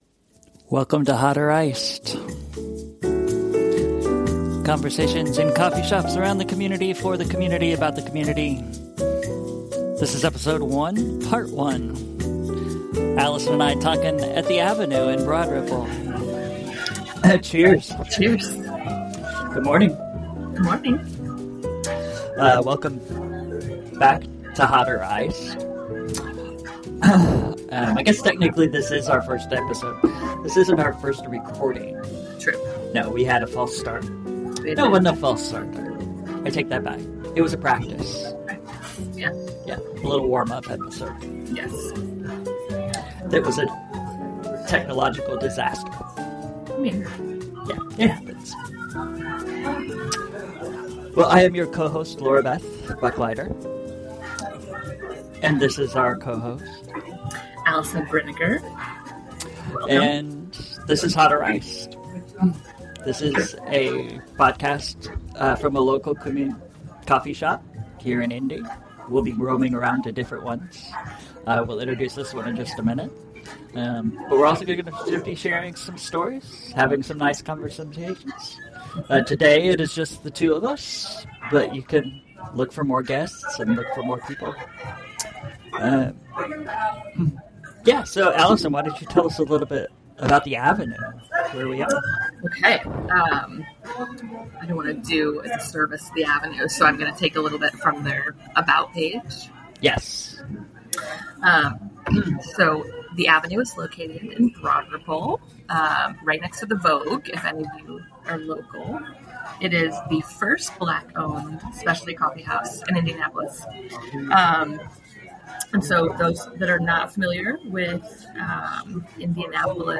We are in community, for community, with stories about community! In part one of each episode, we will introduce the coffee shop and our guest.